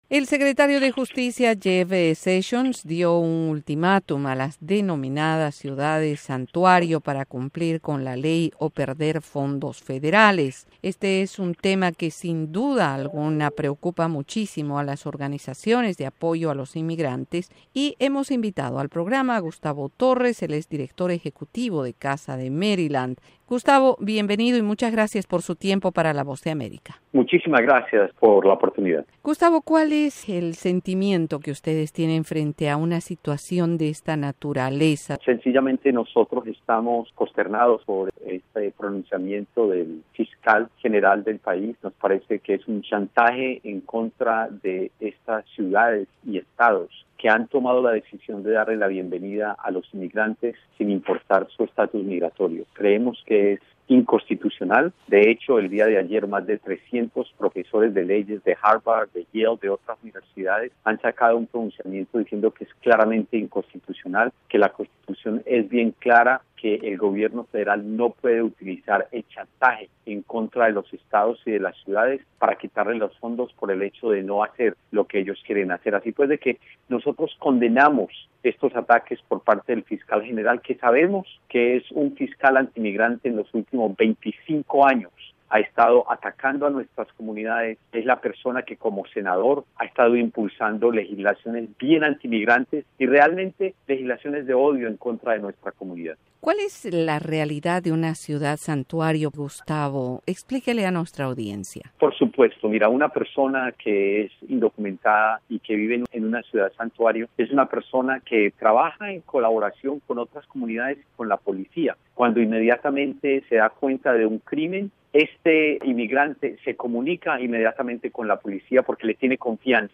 dialoga sobre las "ciudades santuario" Por Voz de América Insertar share El código se ha copiado en su portapapeles.